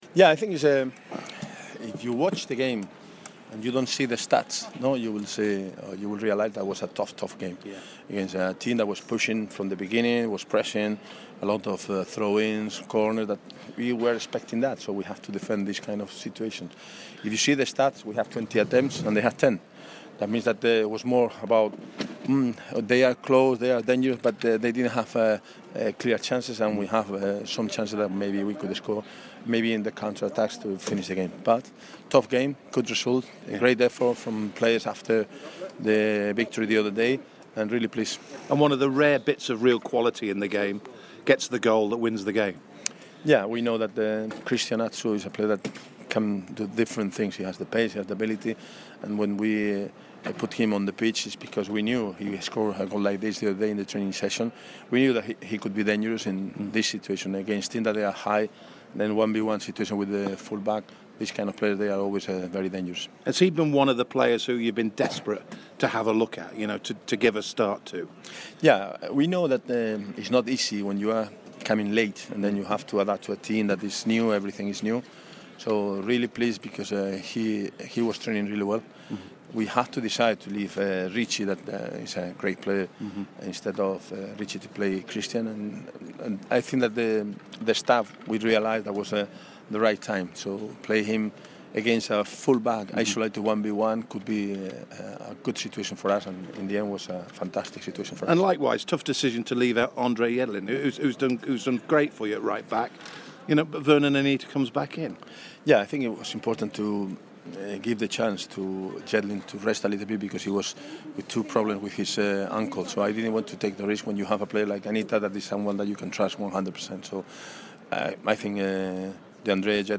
Newcastle manager Rafa Benitez spoke to BBC Newcastle following his side's 1-0 win over Rotherham.